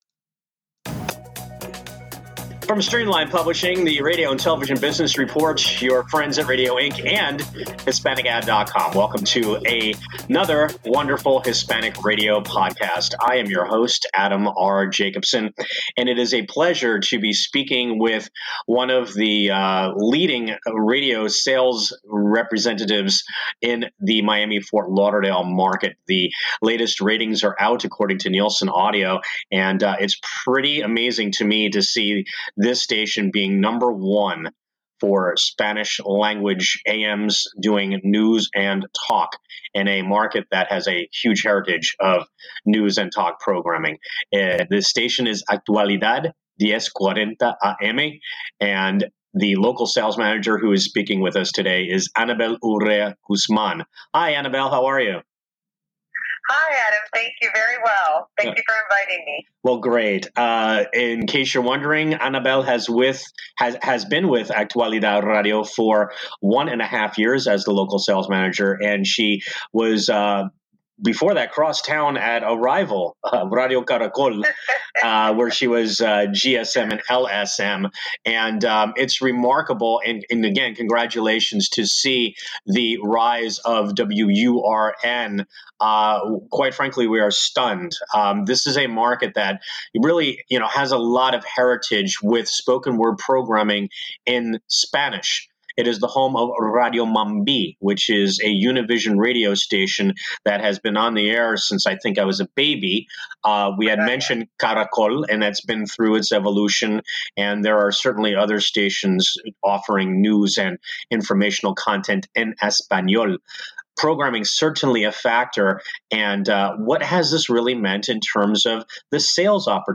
chats by phone